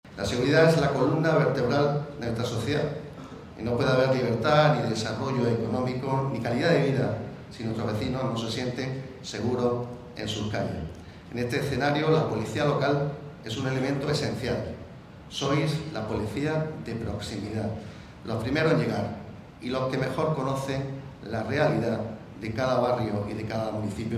ANGEL-ESCOBAR-VICEPRESIDENTE-DIPUTACION.mp3